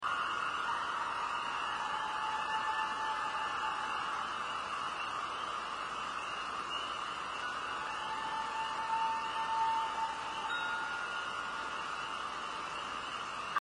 Category: Animals/Nature   Right: Personal
Tags: Wildlife audio recordings Unknow Wildlife Souns